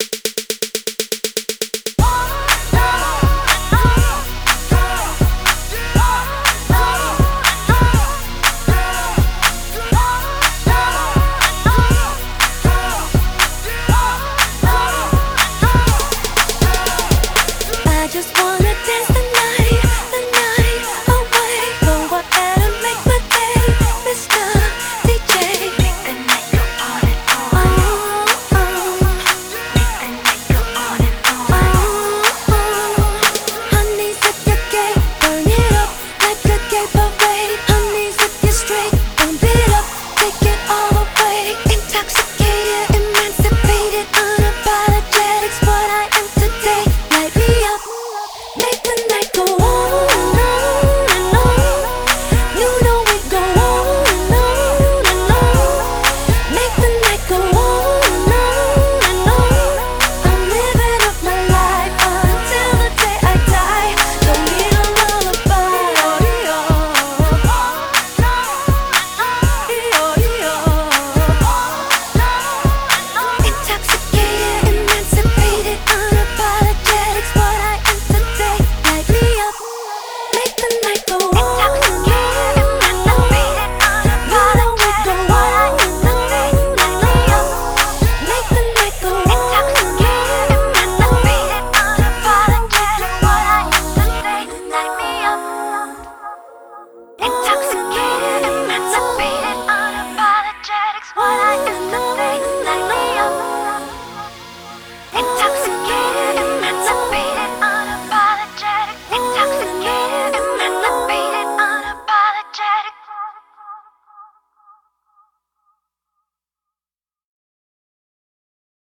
BPM121
Audio QualityPerfect (High Quality)
one of the more upbeat club-like songs